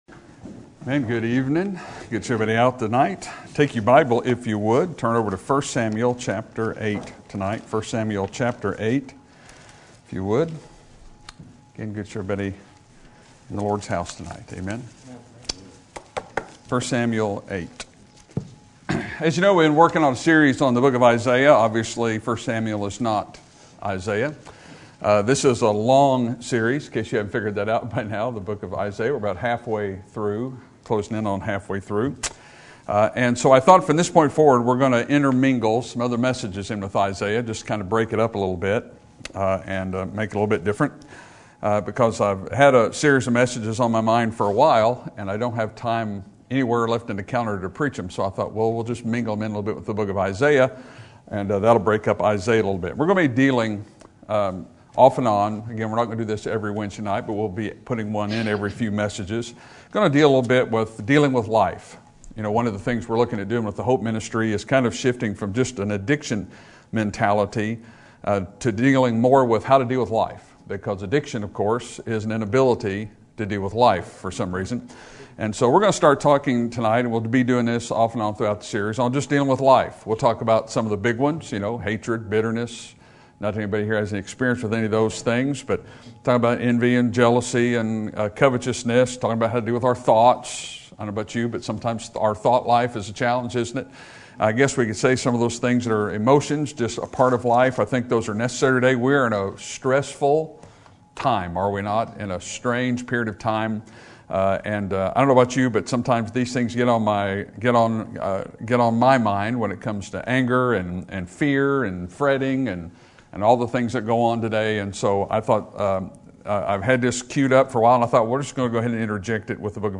Sermon Topic: General Sermon Type: Service Sermon Audio: Sermon download: Download (34.2 MB) Sermon Tags: 1 Samuel Life Rejection Control